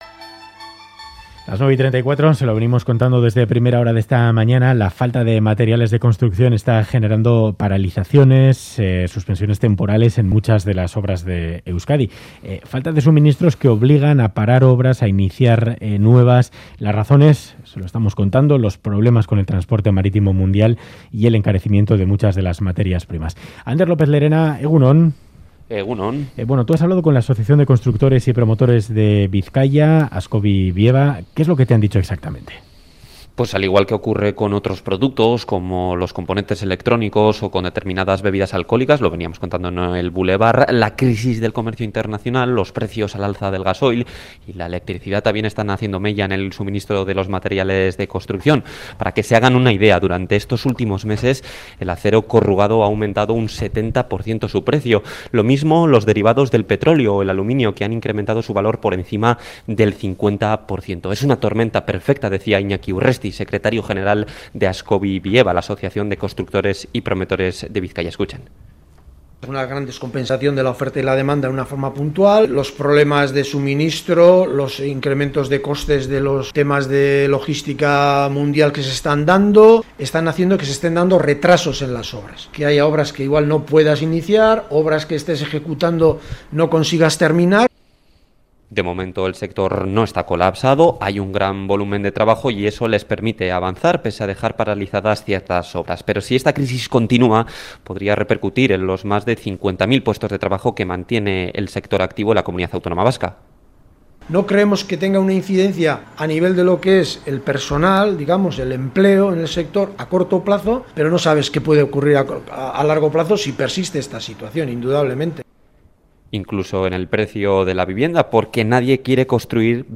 Radio Euskadi ENTREVISTA